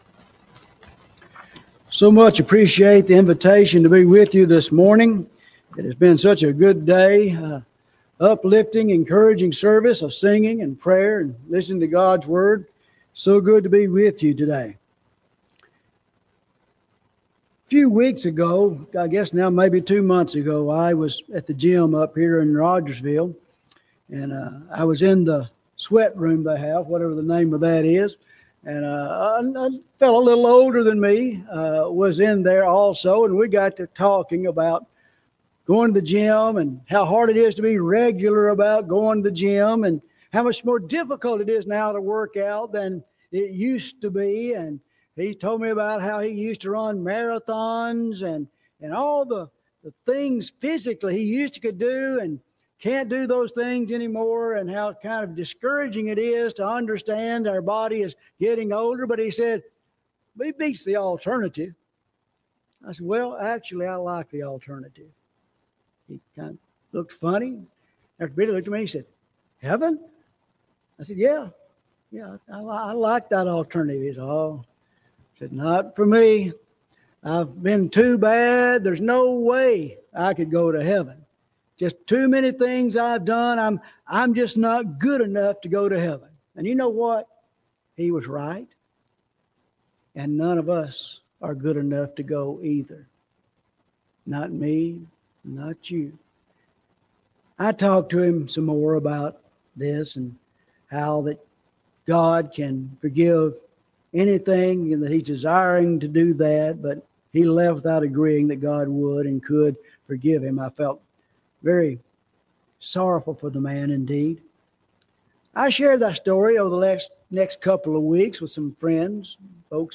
Cedar Grove Church of Christ August 10 2025 AM Sunday Sermon - Cedar Grove Church of Christ